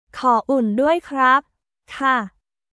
コー ウン ドゥアイ クラップ/カ